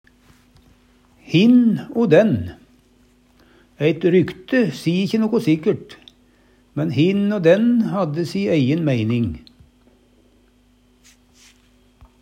DIALEKTORD PÅ NORMERT NORSK hin o dæn ein og annan, nokon kvar Eksempel på bruk Eit rykte si ikkje noko so sikkert, men hin o dæn hadde si eijen meining.